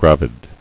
[grav·id]